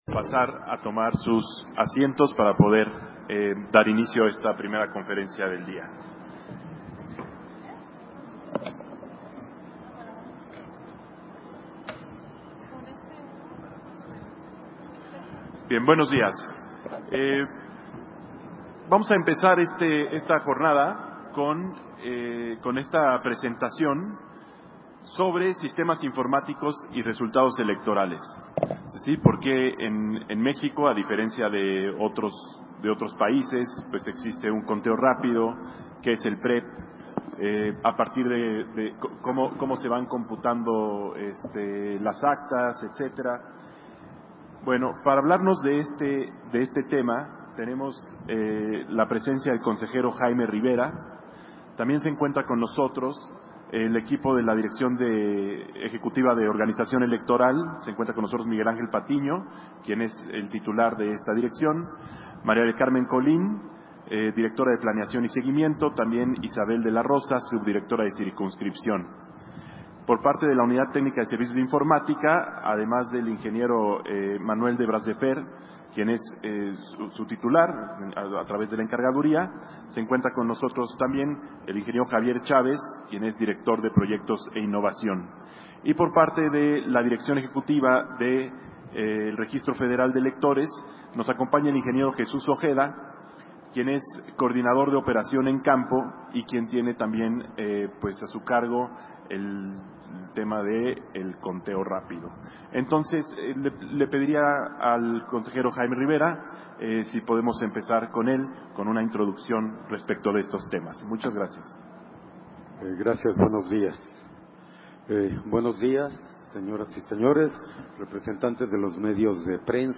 Conferencia de prensa sobre los sistemas informáticos y resultados electorales en el marco del Proceso Electoral Federal 2023-2024